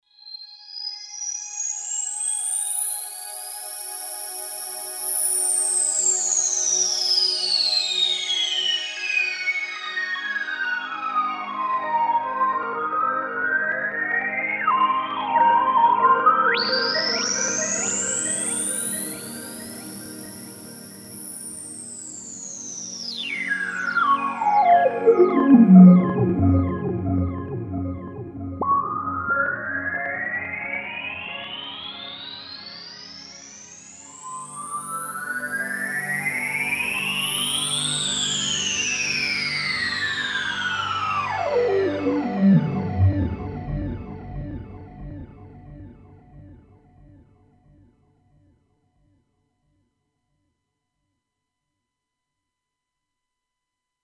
Patch 100 PAD
- Reso Sweep
TOP8_Reso_Sweep.mp3